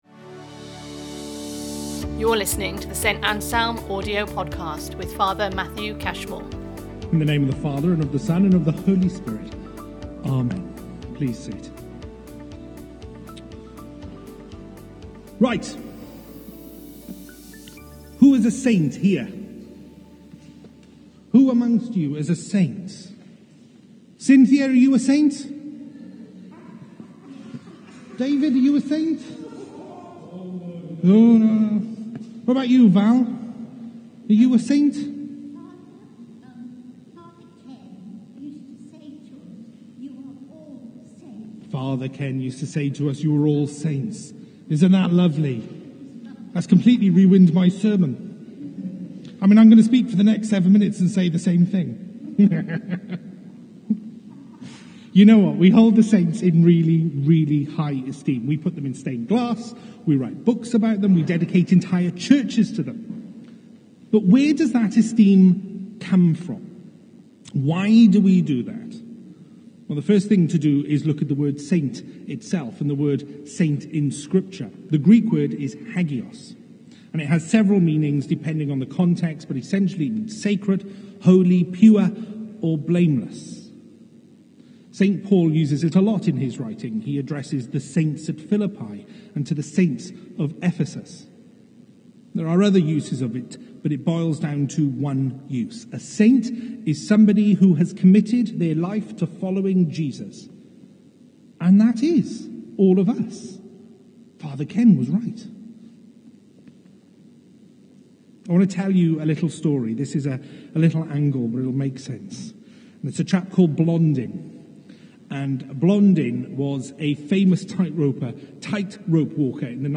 Series Sunday Sermons